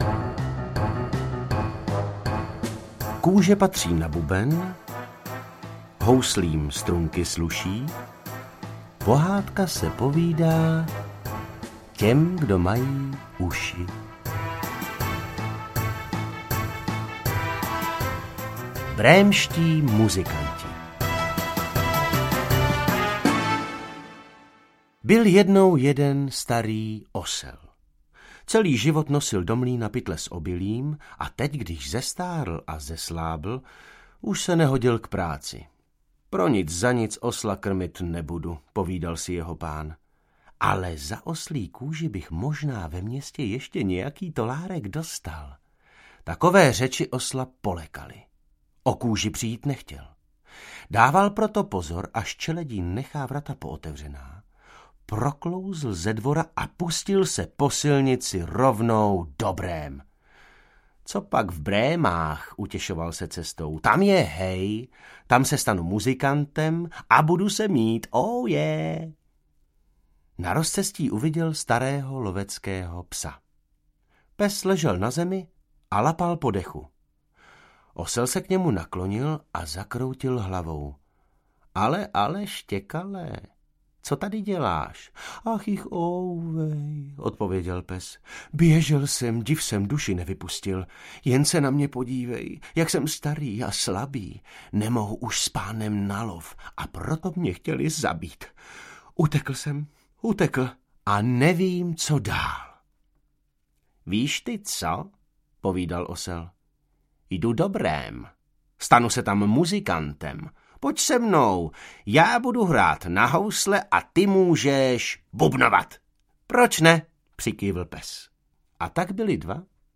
Ukázka z knihy
• InterpretDavid Novotný